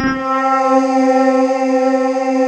Index of /90_sSampleCDs/USB Soundscan vol.28 - Choir Acoustic & Synth [AKAI] 1CD/Partition D/07-STRATIS